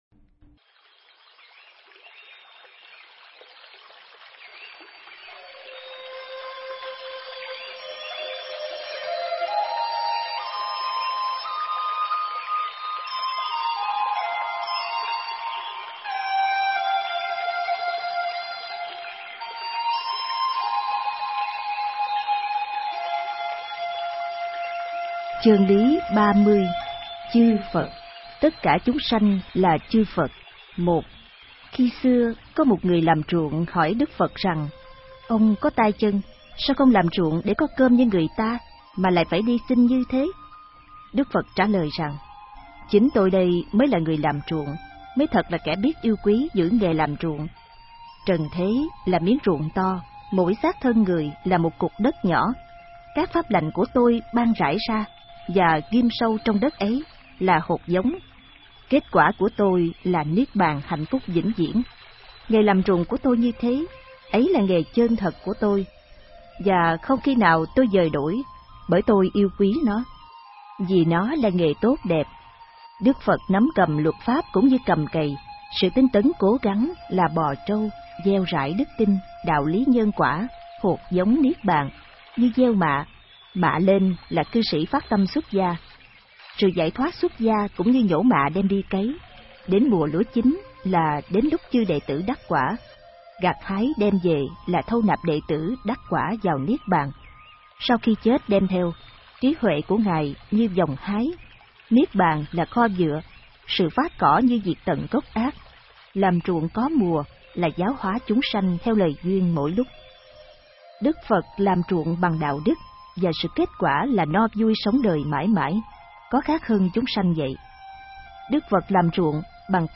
Nghe sách nói chương 30.